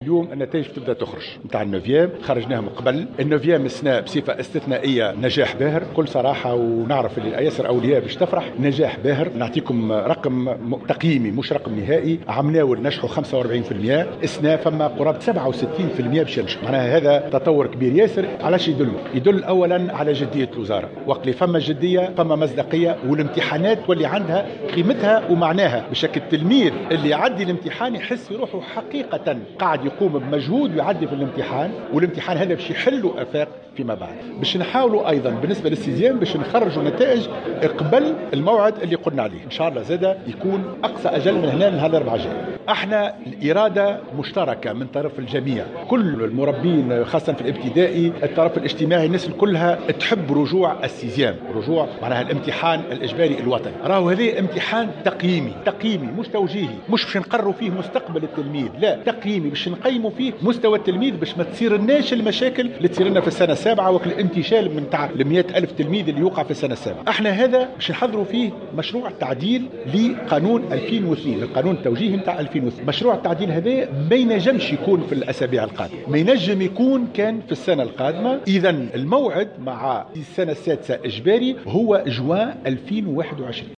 وقال بن سالم في تصريح للجوهرة أف أم على هامش إمضاء الوزارة لاتفاقية تعاون مع نقديات تونس، أن عدد الناجحين في مناظرة النوفيام تطور هذه السنة من 45% إلى حوالي 67% وهو ما يُعتبر نجاحا باهرا حسب قوله.